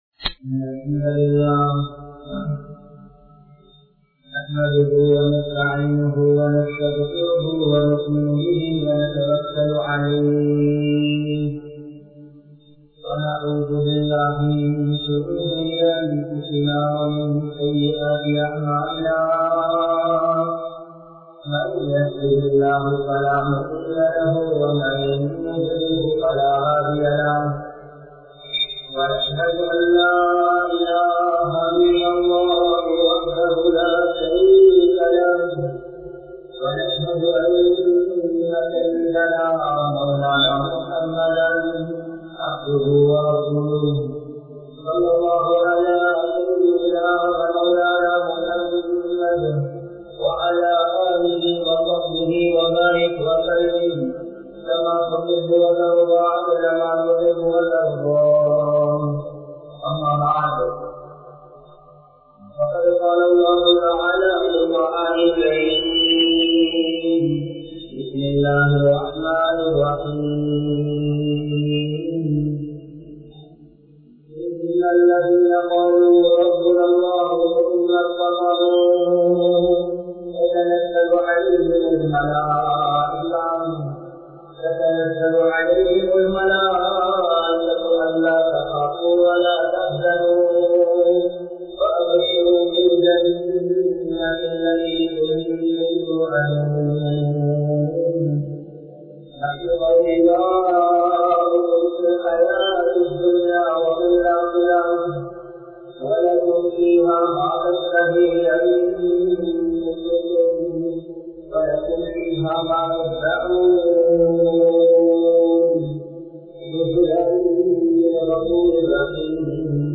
Vaalifarhalai Othukkaatheerhal (வாலிபர்களை ஒதுக்காதீர்கள்) | Audio Bayans | All Ceylon Muslim Youth Community | Addalaichenai